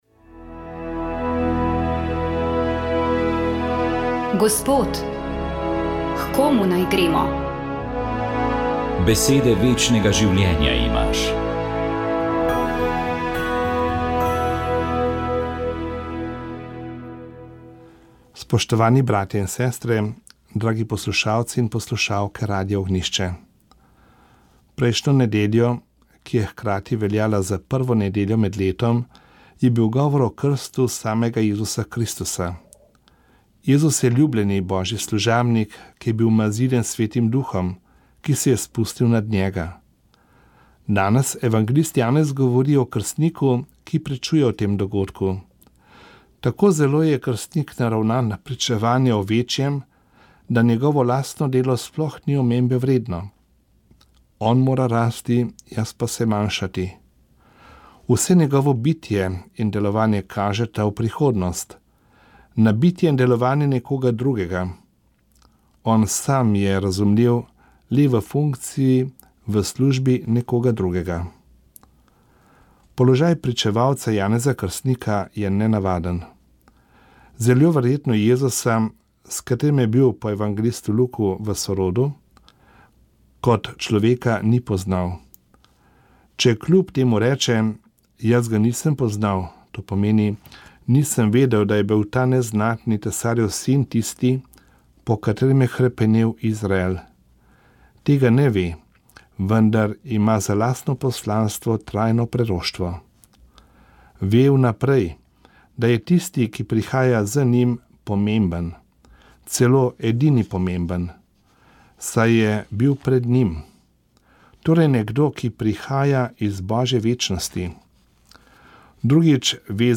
Duhovni nagovor
Božični nagovor
Upokojeni nadškof Alojz Uran je pripravil razmišljanje ob božiču, prazniku Luči in prazniku spomina na Jezusovo rojstvo.